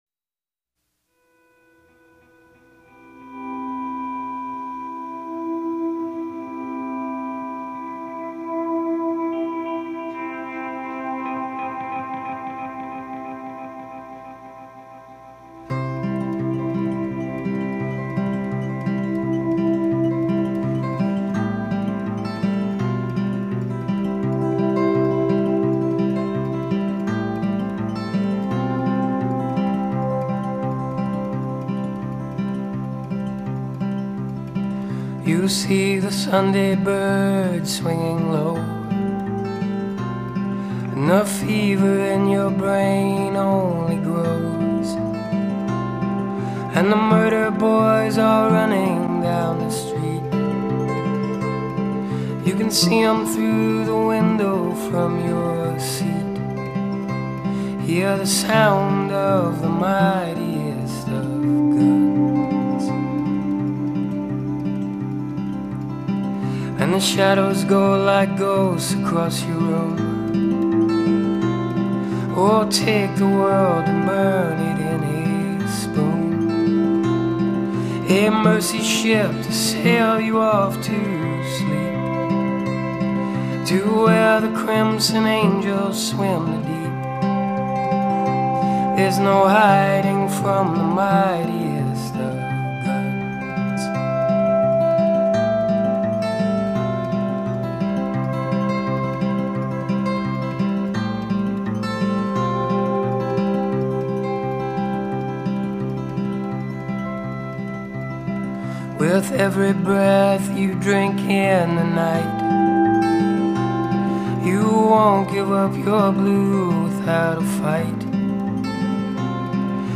acoustic-guitar-fueled gentility